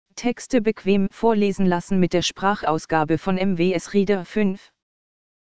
Sprecher für das Vorleseprogramm MWS Reader
Microsoft Speech Platform – Runtime Language (Version 11)